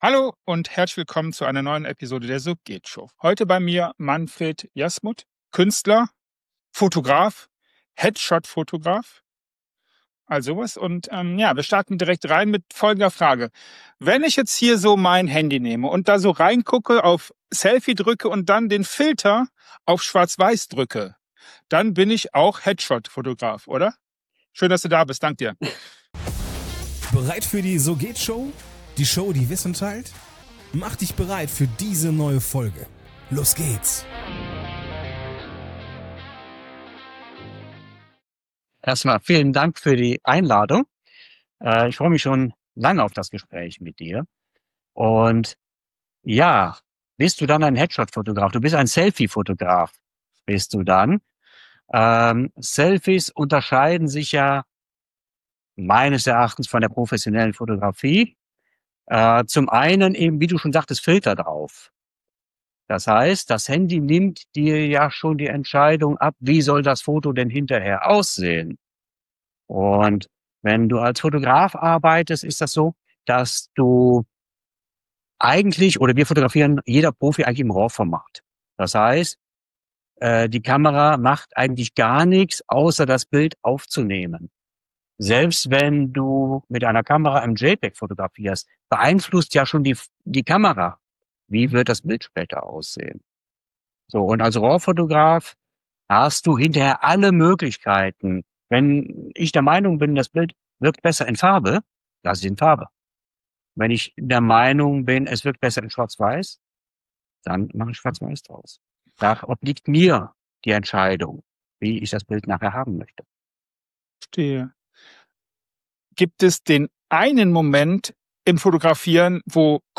Mein Interviewgast hat 45 Sekunden für seine Antwort.
Keine Nachbearbeitung, keine zweiten Versuche – was gesagt wird, bleibt.